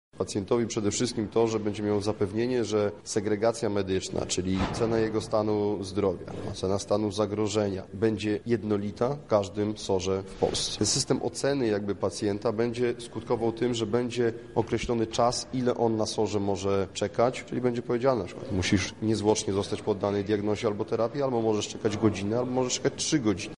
O tym jakie korzyści może to przynieść pacjentom mówi wiceminister w Ministerstwie Zdrowia Sławomir Gadomski: